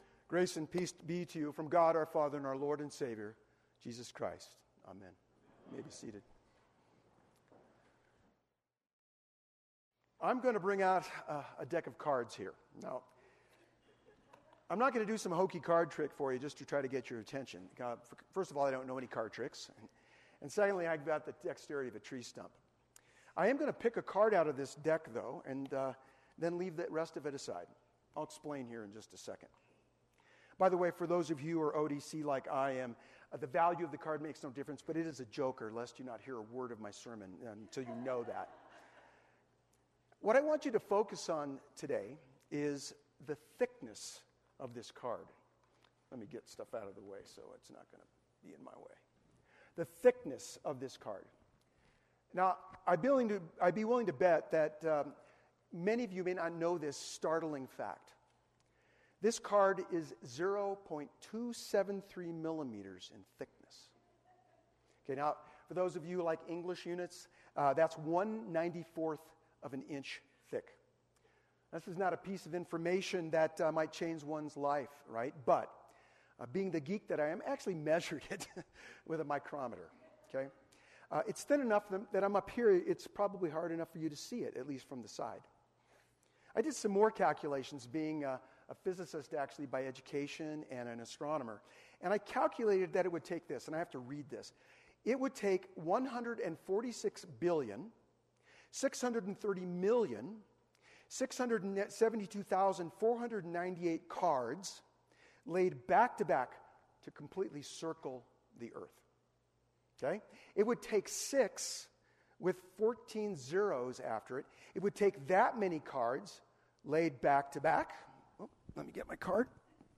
Ninth Sunday after Pentecost